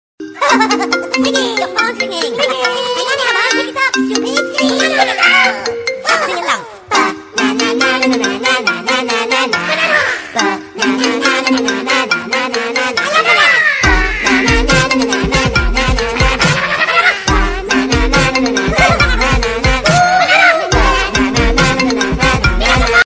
หมวดหมู่: เสียงเรียกเข้า
ที่ร่าเริงและติดหู